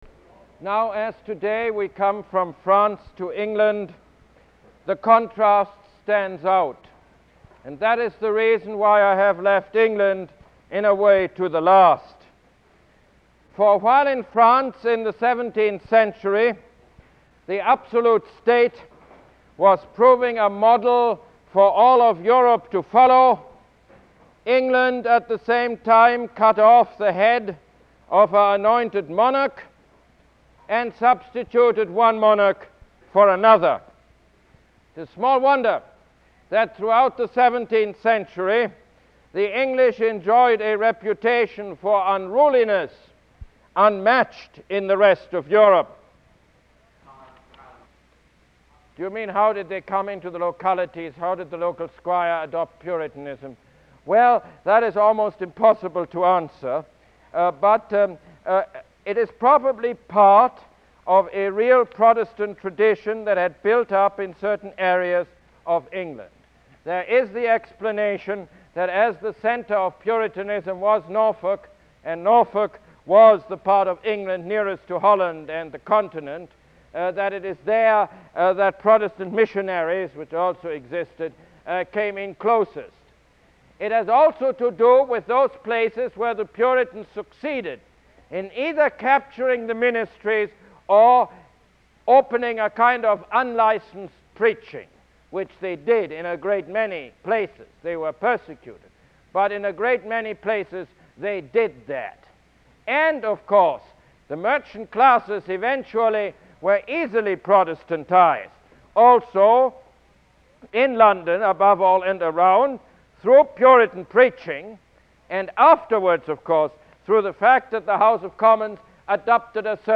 Lecture #14 - England Before the Revolution